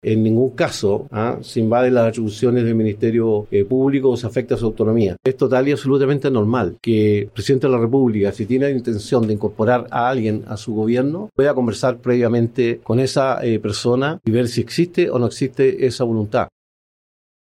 En conversación con Bío Bío TV, Alvarado defendió ambas designaciones y descartó cualquier irregularidad o conflicto de interés, subrayando que se trata de procesos habituales en la conformación de un gabinete presidencial.